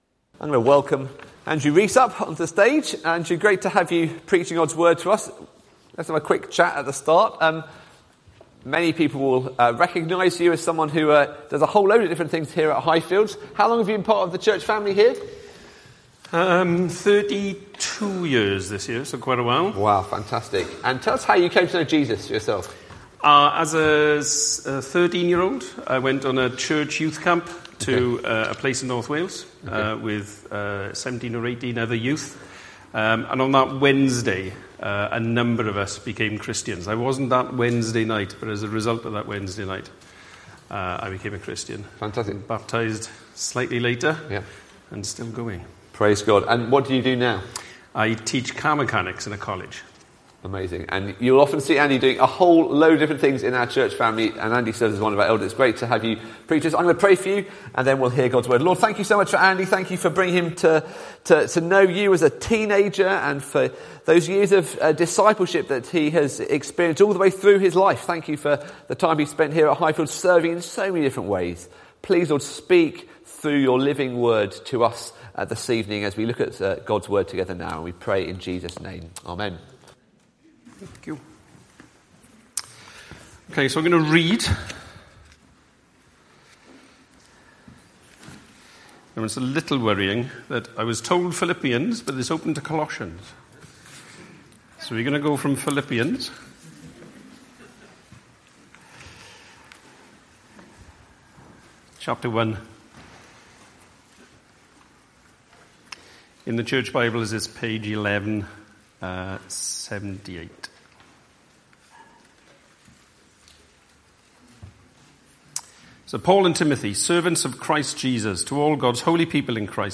Philippians 1:9-11; 07 July 2024, Evening Service. Series theme: Prayer and Praise https